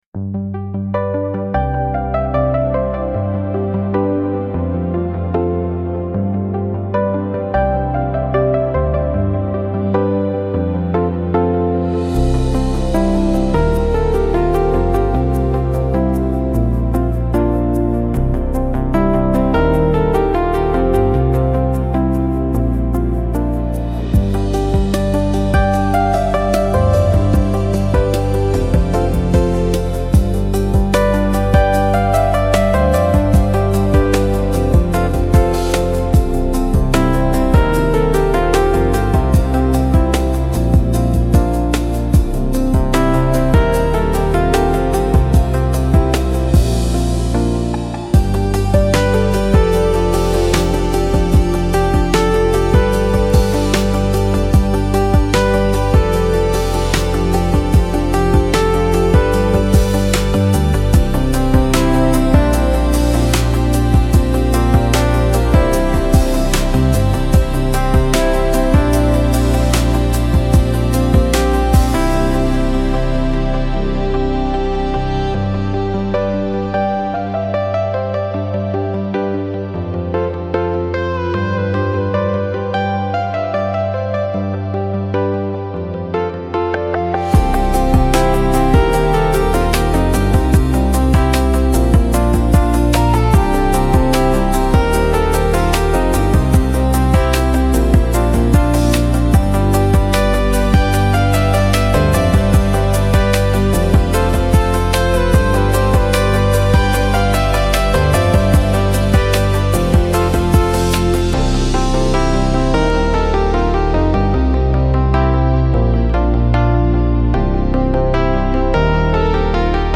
Upbeat Cheerful Synth Downtempo Instrumental Music
Genres: Background Music
Tempo: medium